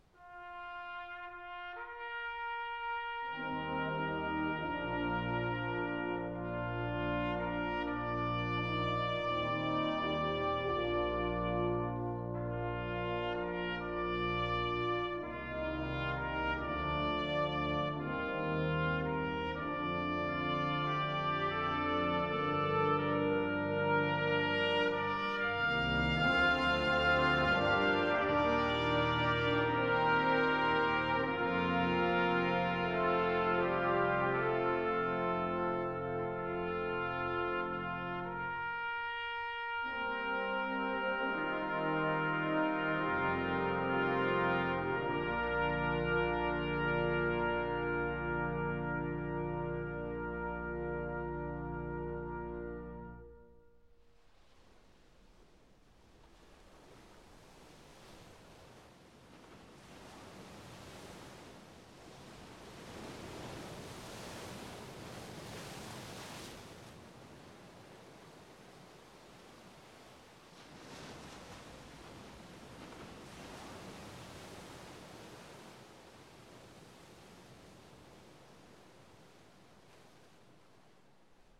The creation of our country’s most revered bugle call has been credited for many years to General Daniel Butterfield, commander of the Third Brigade, First Division, Fifth Army Corps of the Army of the Potomac during the Civil War.
Listen/Download "Taps" (Solo Version)